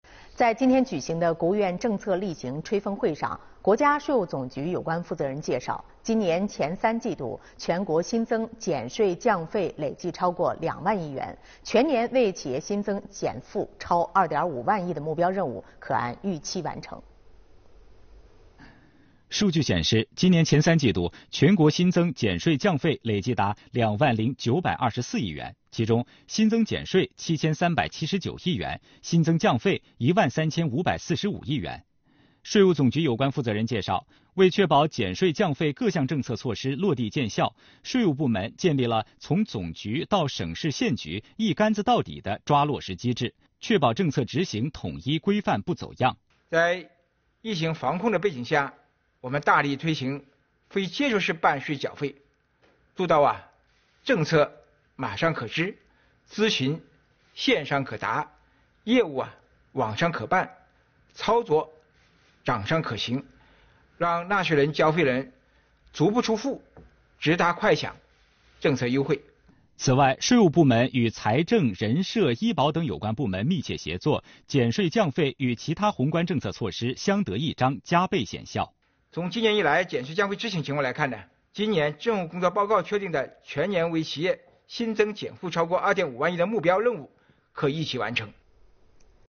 11月12日，国务院新闻办举办国务院政策例行吹风会，介绍财政资金直达机制和减税降费工作进展及取得成效。财政部副部长许宏才和有关司局负责同志、国家税务总局收入规划核算司司长蔡自力参加并回答记者提问。